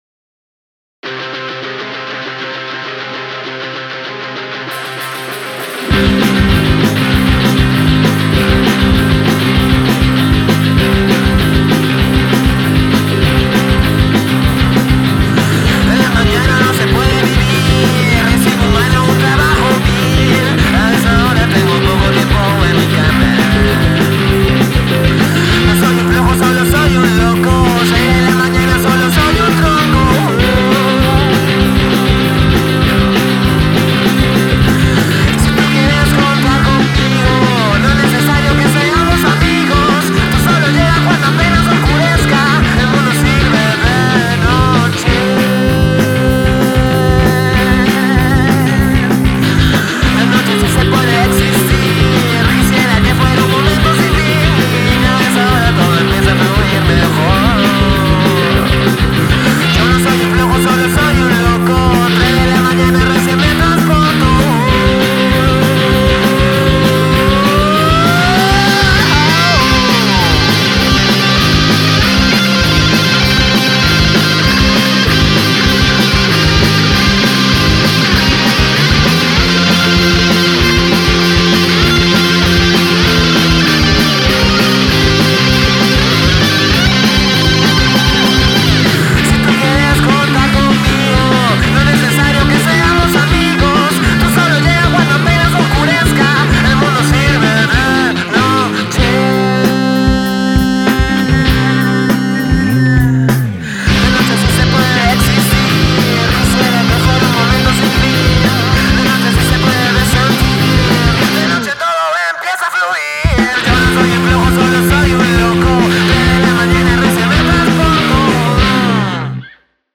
rocanrol en su estado más puro.
Rock and roll